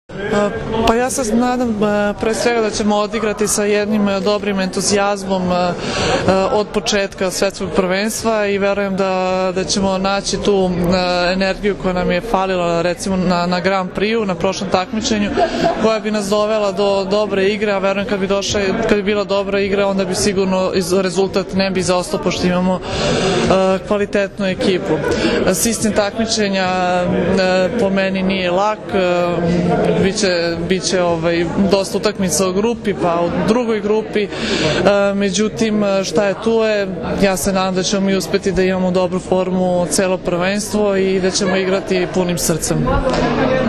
IZJAVA JELENE NIKOLIĆ